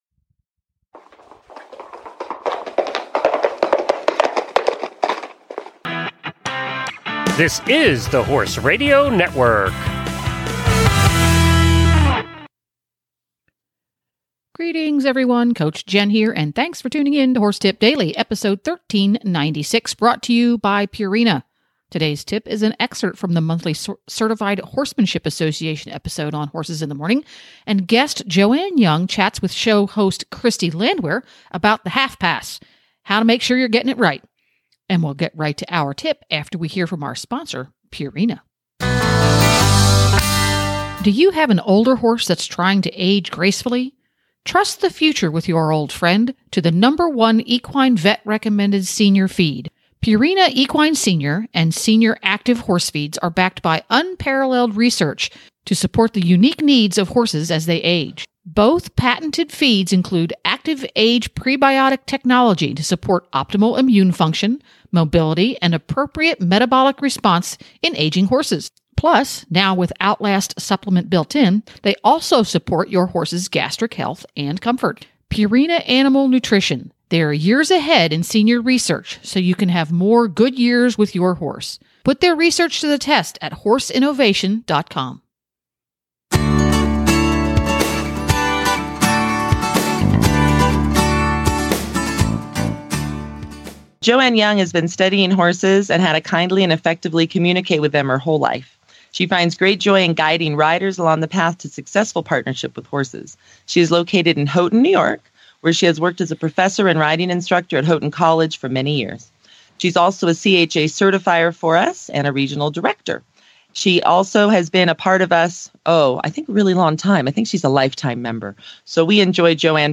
Today's tip is an excerpt from the monthly CHA episode on HITM.